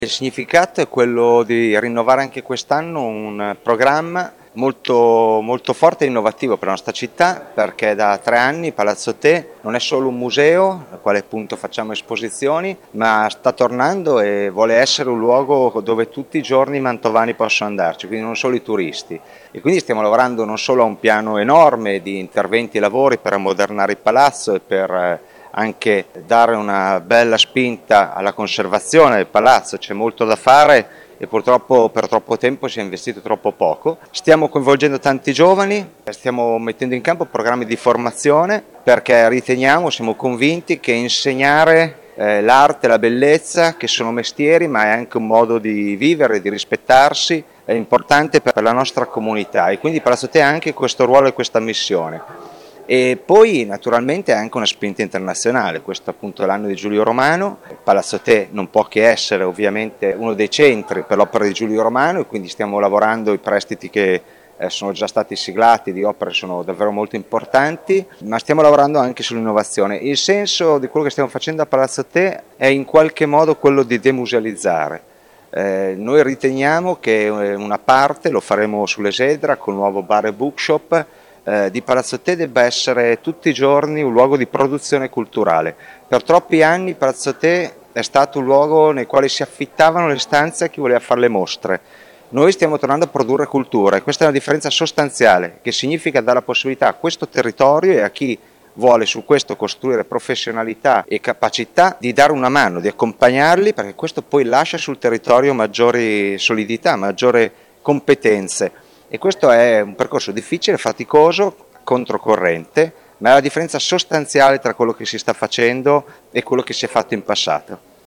Molti altri sono i progetti in corso d’opera a Palazzo Te, come confermato dalle personalità intervistate dal nostro corrispondente
Mattia Palazzi, sindaco di Mantova
Mattia-Palazzi-sindaco-di-Mantova.mp3